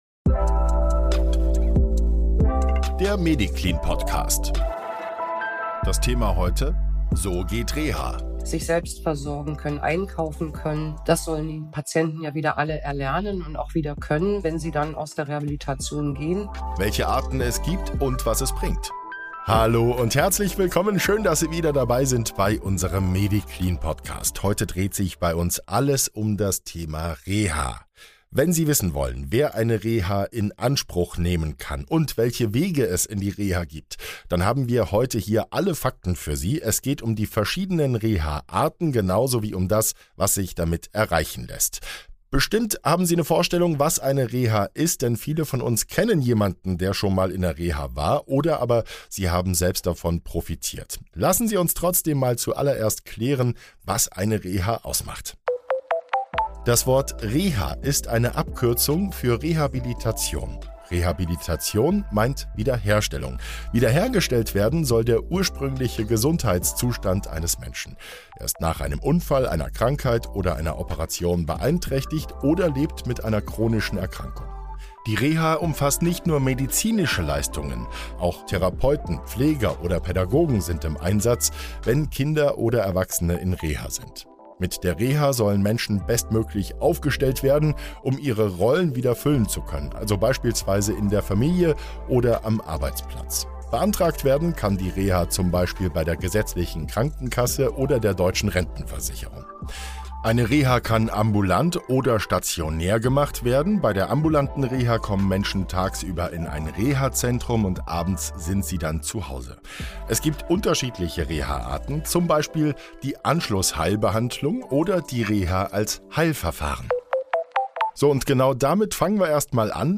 Beschreibung vor 3 Jahren Drei MEDICLIN-Expert*innen erklären in dieser Folge, welche Reha-Arten es gibt, wann man in eine Reha kommt und was man von einer guten Reha erwarten kann.